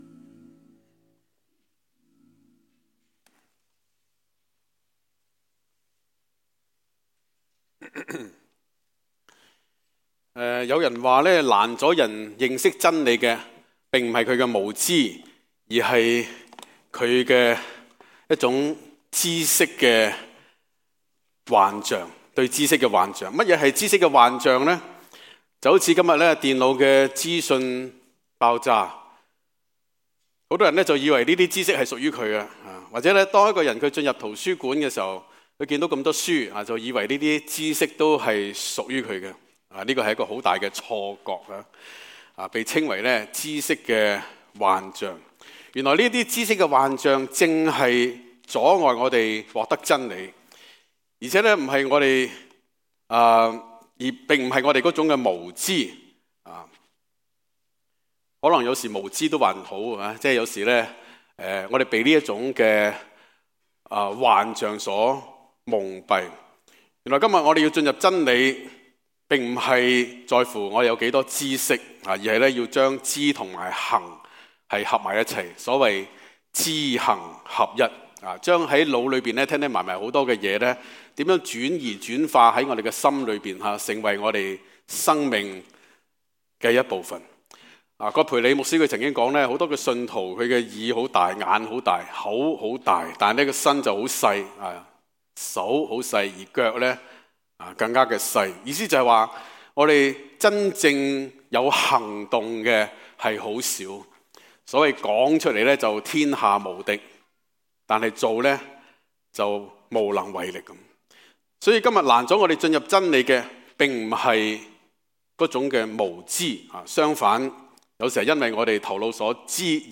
在： Sermon